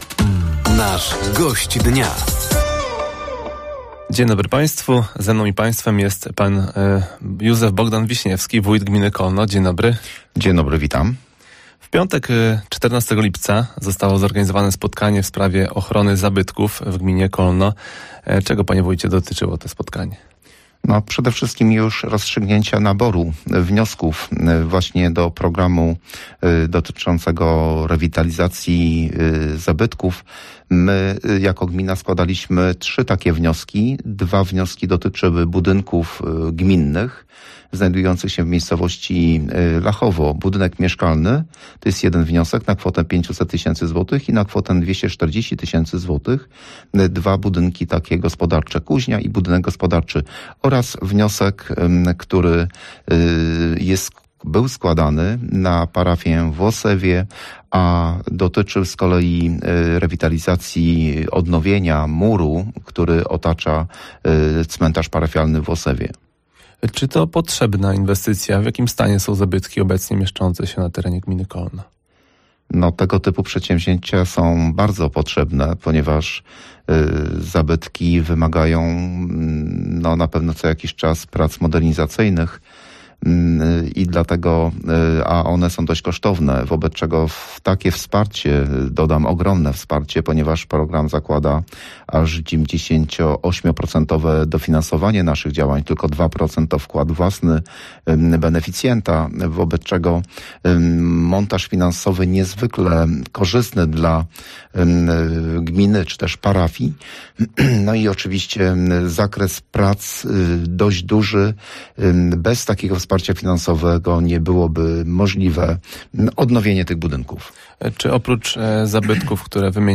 Gościem Dnia Radia Nadzieja był Wójt Gminy Kolno, Józef Bogdan Wiśniewski. Tematem rozmowy była jednostka wojskowa, działania Kół Gospodyń Wiejskich czy wsparcie straży pożarnej oraz zabytków.